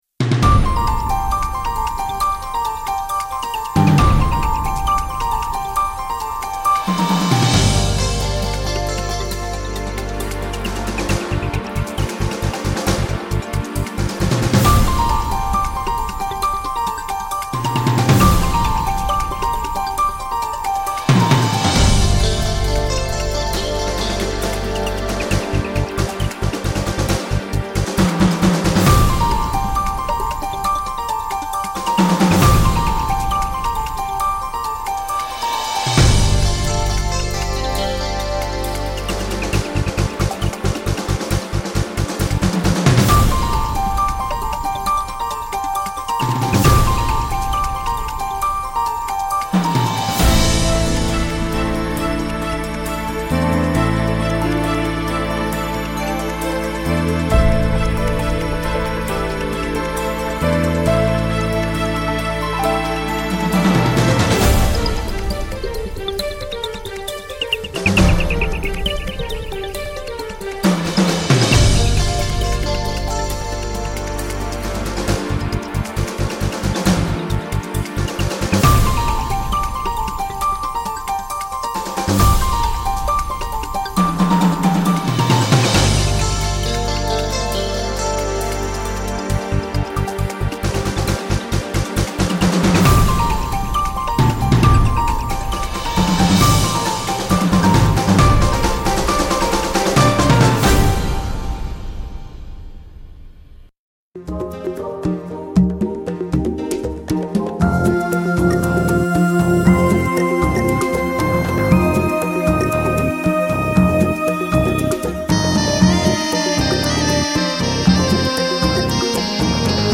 Termiz: Afg'on konsuli bilan muloqot